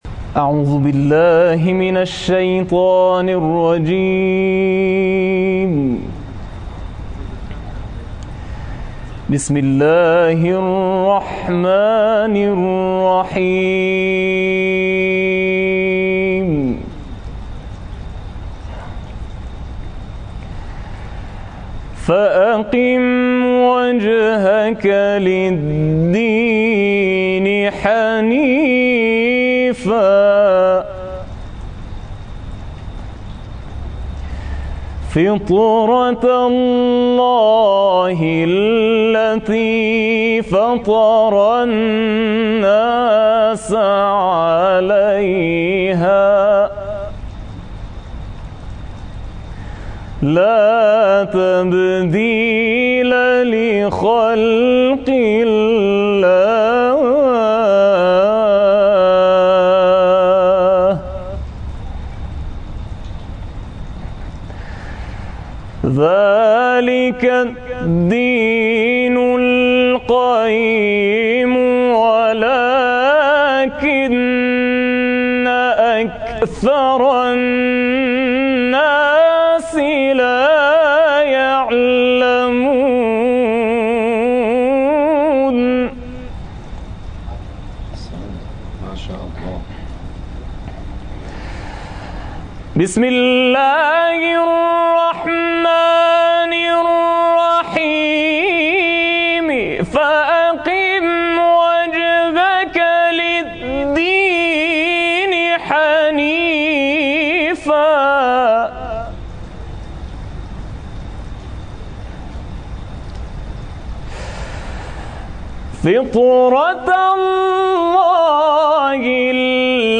آیات 30 الی 32 سوره مبارکه روم را بسیار زیبا تلاوت کرد